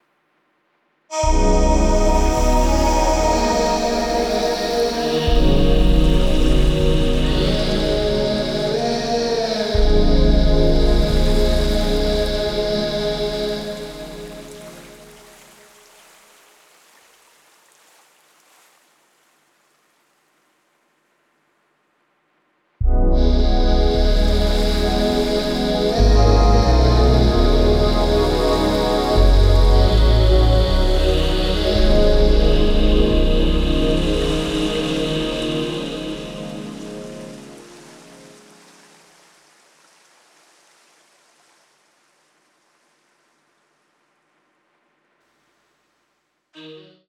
ディープなニューエイジ/アンビエント的アプローチの楽曲を展開